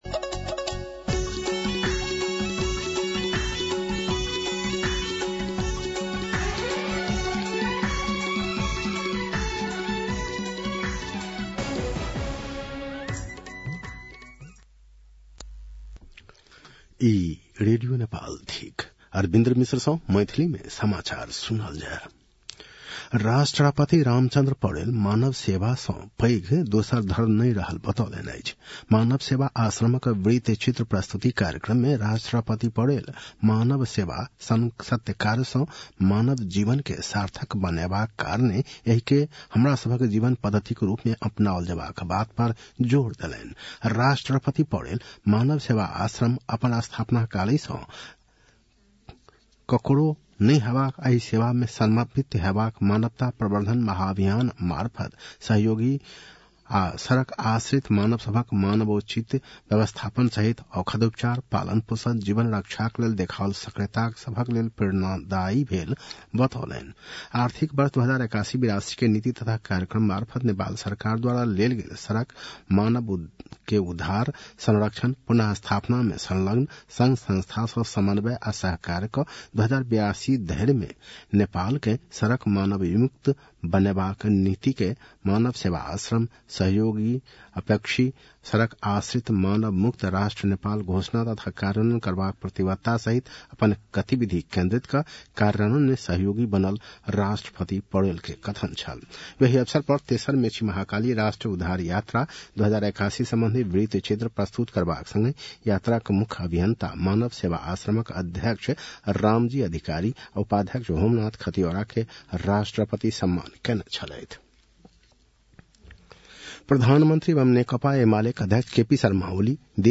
मैथिली भाषामा समाचार : २२ पुष , २०८१